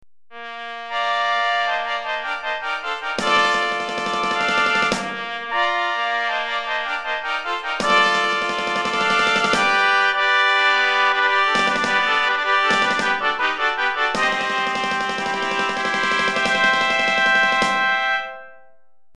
4 Trompettes